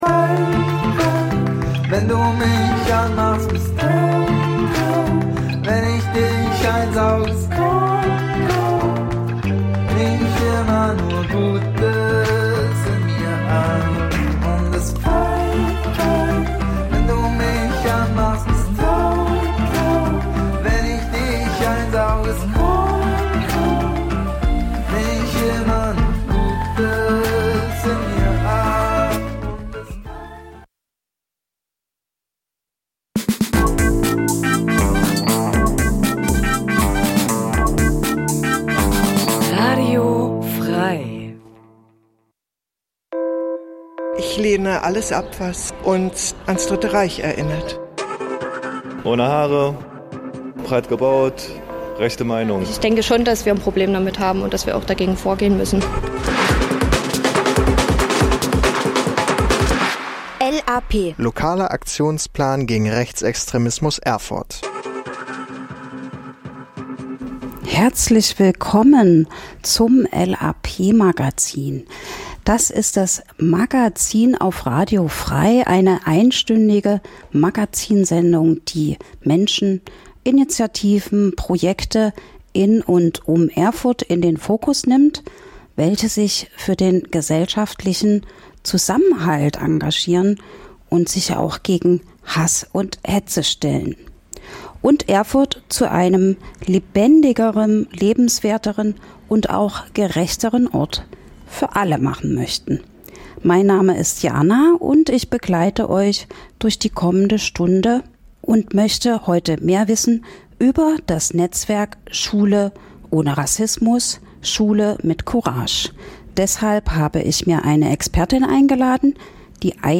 In der Sendung h�rt ihr verschiedene Beitr�ge rund um Demokratie und gegen Rechts, z. B. - Interviews zu aktuellen Themen - Veranstaltungshinweise - Musikrubrik "Coole Cover" --- Die Sendung l�uft jeden zweiten Mittwoch 11-12 Uhr (Wiederholung: Donnerstag 20 Uhr) und informiert �ber Themen, Projekte und Termine gegen Rechts in Erfurt und Umgebung.